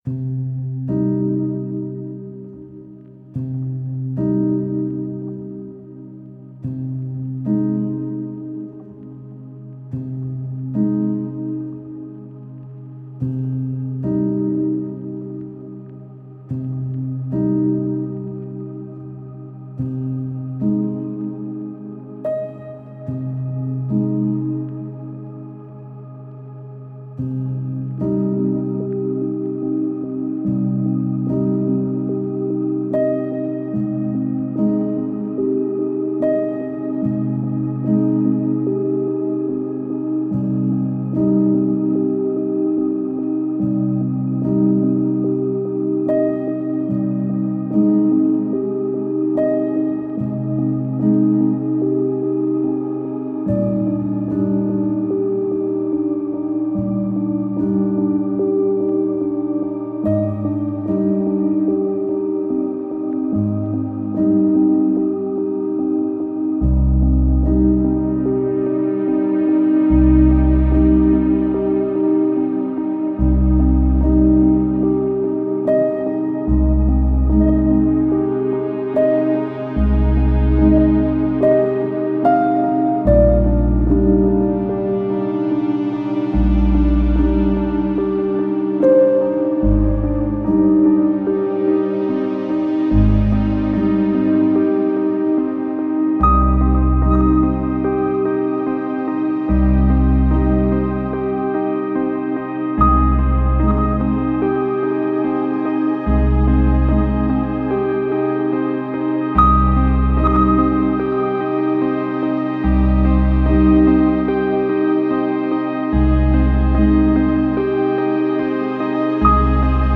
Pensive piano theme build up alongside spacy synths.